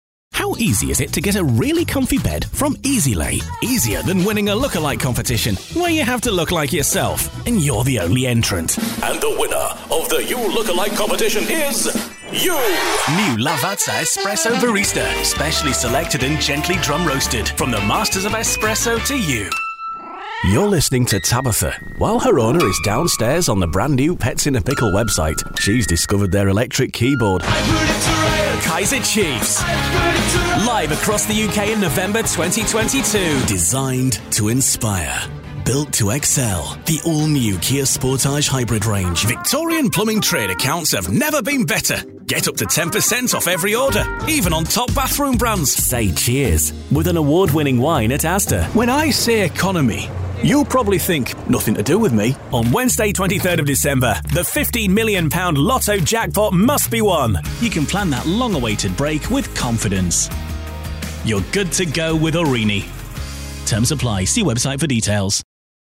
Englisch (Britisch)
Kommerziell, Cool, Freundlich, Warm, Corporate
Kommerziell
Frisch, warm, sanft, freundlich und gesprächig
Dynamischer Verkauf und beeindruckende tiefe Stimme
Neutral, mit Received Pronunciation Akzent
Sowohl neutrales nordenglisches als auch echtes Yorkshire-Akzent (West Yorkshire/Leeds)